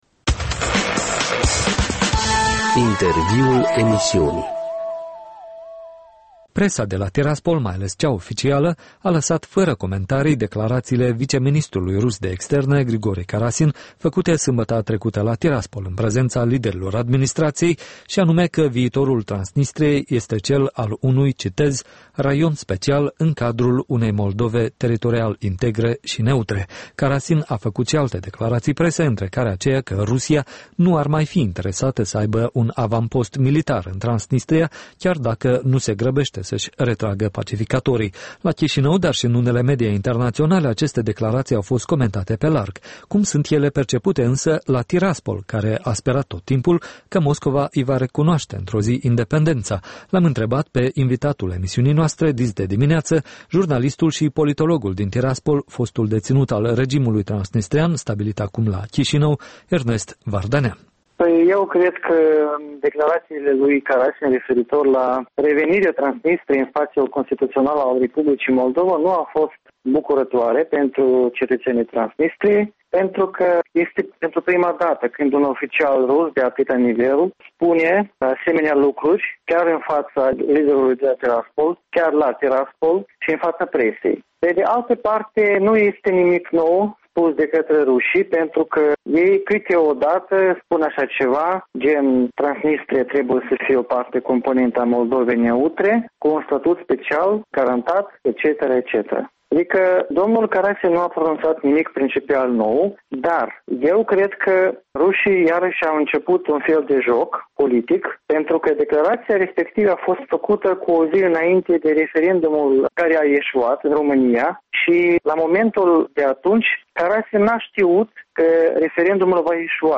Un interviu despre situația din Transnistria